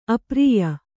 ♪ aprīya